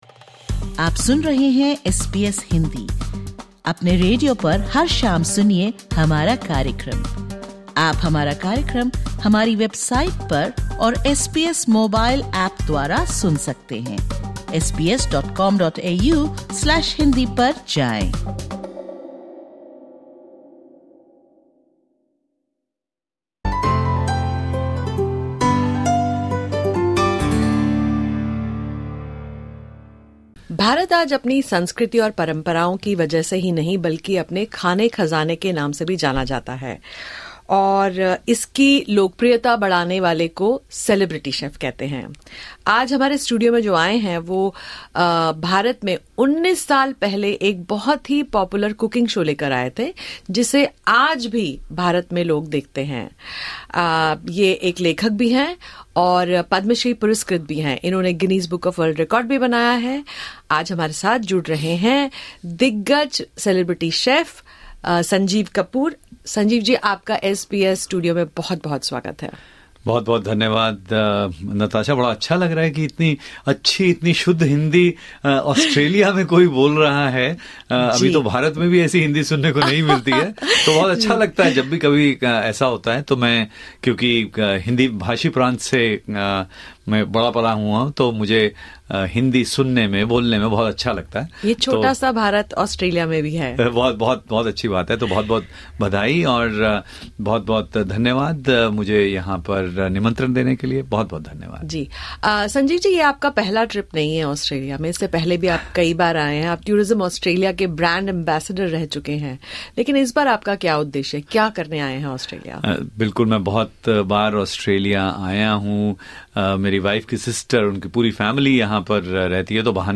India's celebrity chef Sanjeev Kapoor viisted SBS Studio in Melbourne.